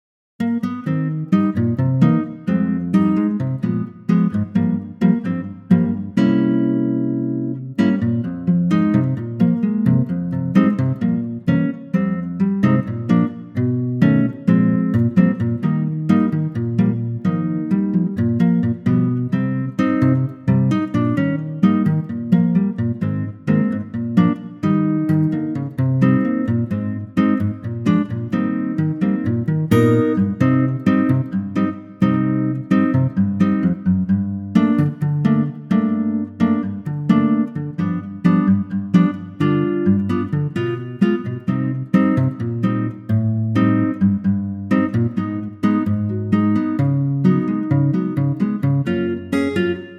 key - Eb - vocal range - Bb to G